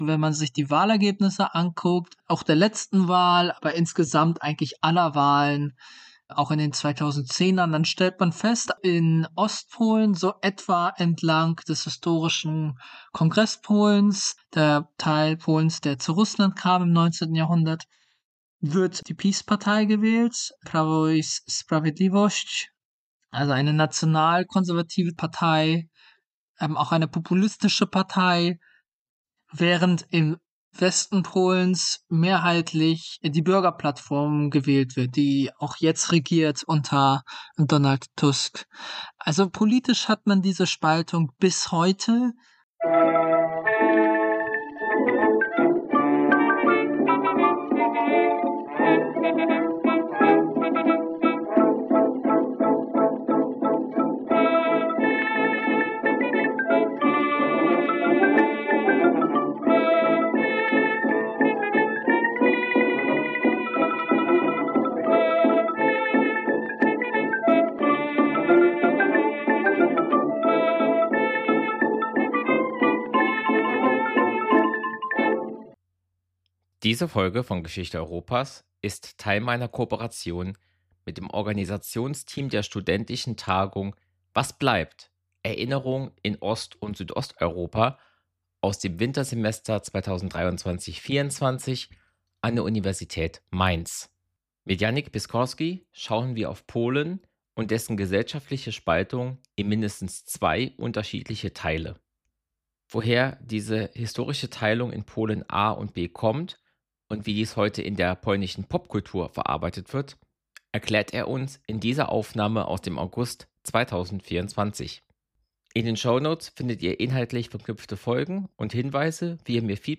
Ein Podcast über die Geschichte Europas. Gespräche mit Expert:innen, angefangen beim geologischen Entstehen der europäischen Halbinsel bis hin zum heutigen Tag.